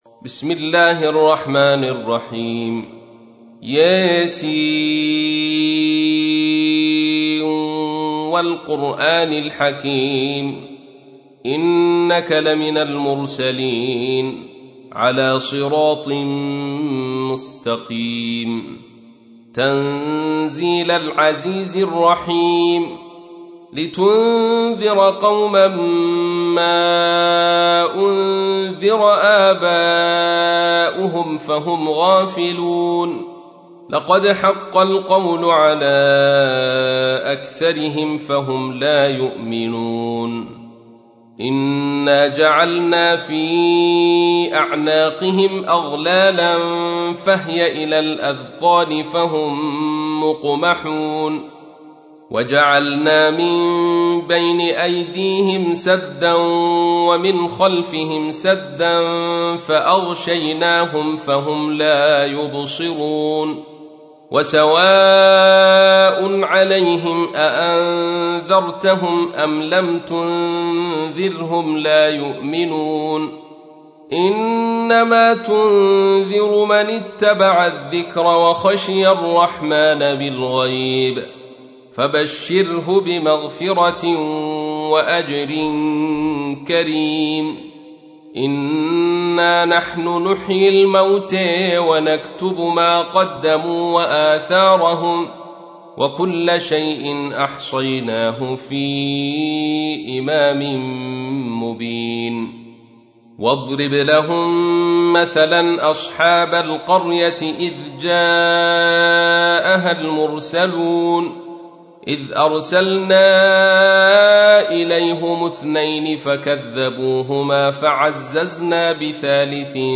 تحميل : 36. سورة يس / القارئ عبد الرشيد صوفي / القرآن الكريم / موقع يا حسين